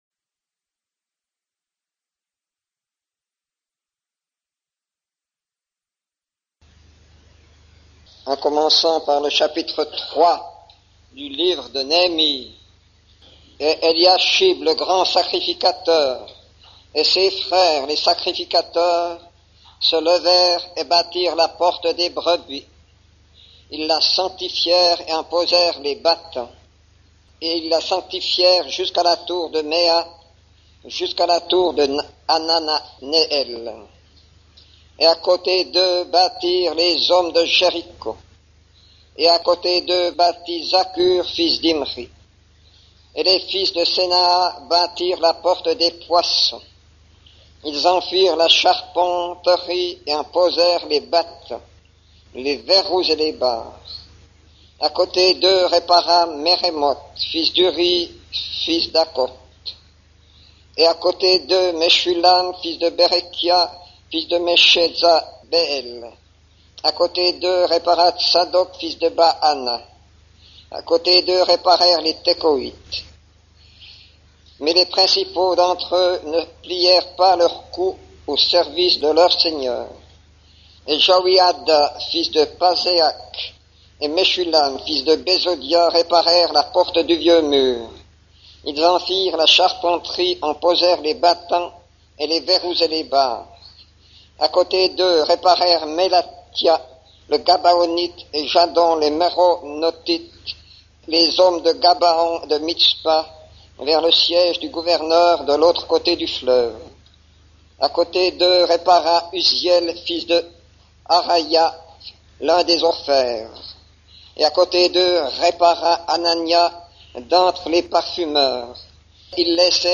Etudes Tramelan 1990 Nehemie Reunion 4.mp3